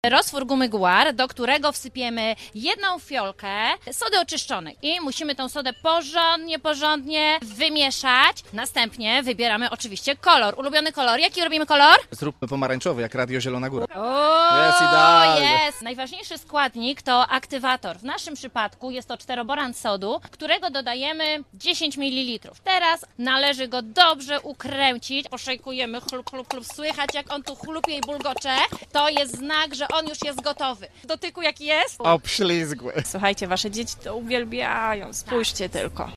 A wszystkiemu przyglądali się nasi reporterzy z Winobraniowego Studia Radia Zielona Góra: